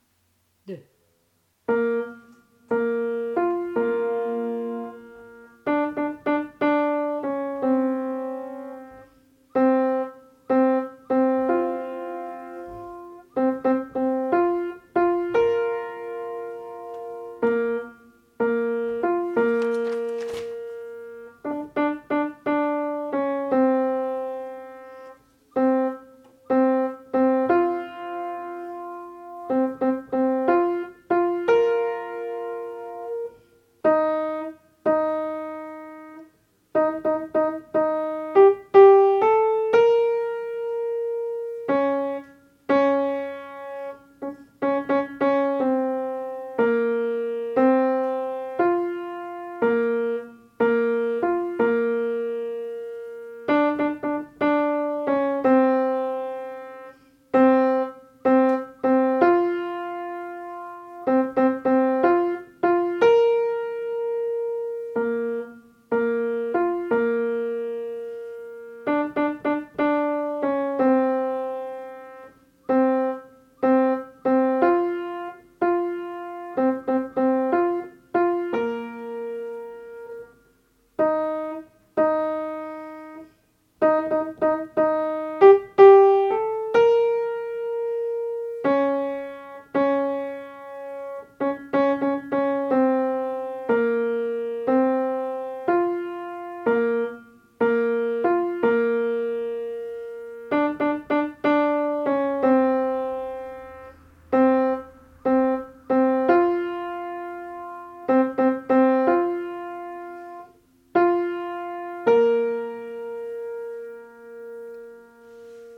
basse
Chanson_de_Lara_basses.mp3